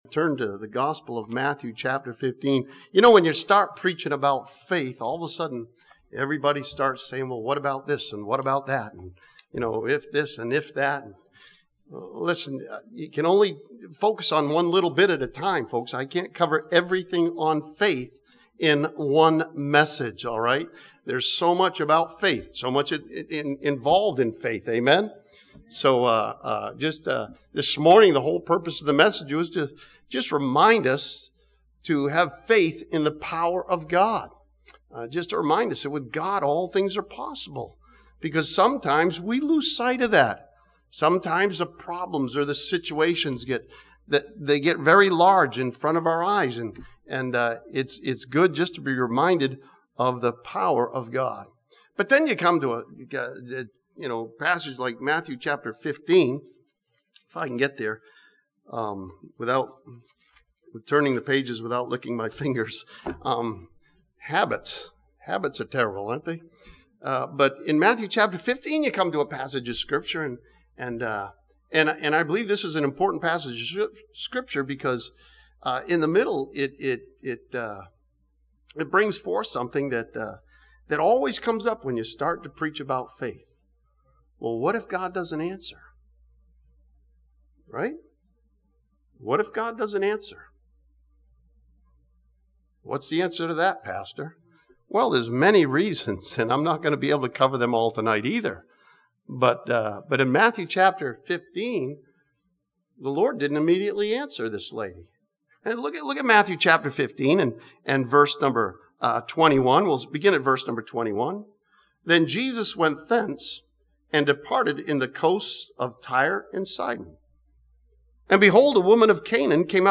This sermon from Matthew chapter 15 continues to look at faith and sees that true faith is a prevailing faith.